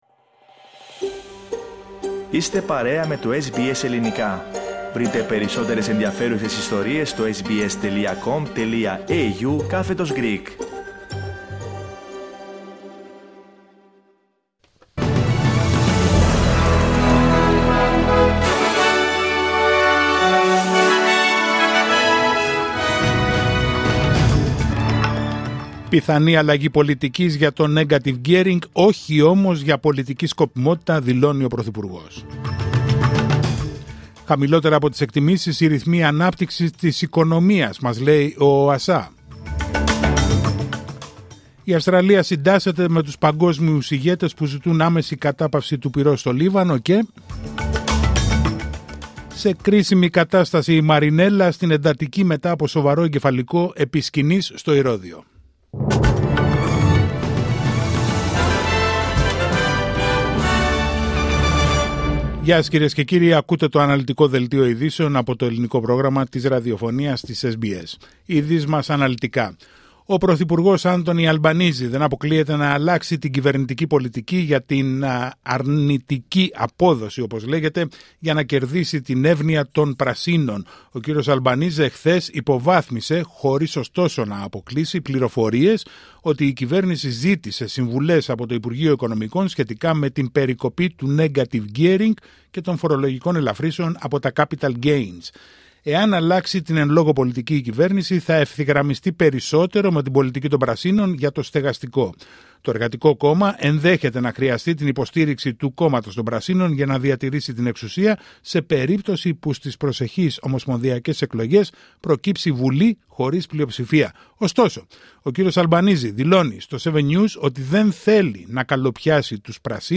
Δελτίο ειδήσεων Πέμπτη 26 Σεπτεμβρίου 2024